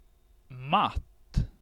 Recordings and example transcriptions in this help are in Sweden Swedish, unless otherwise noted.
matt cut